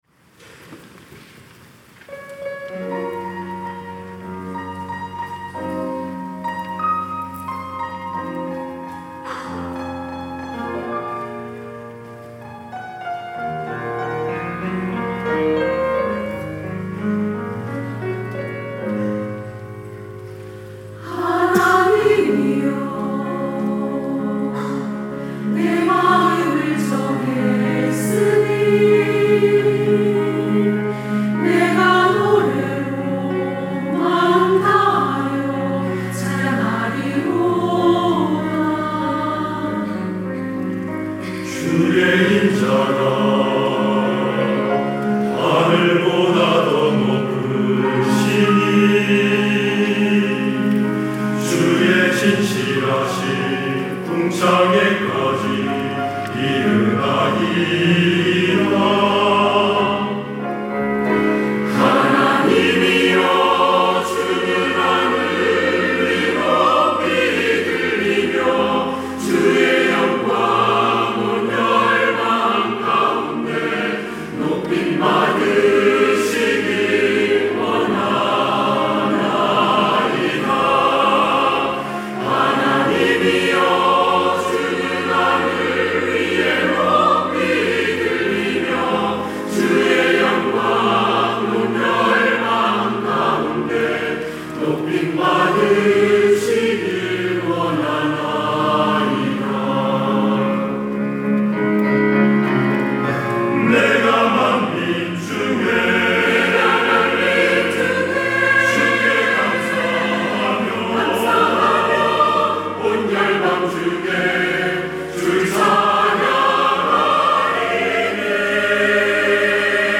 할렐루야(주일2부) - 시편 108편
찬양대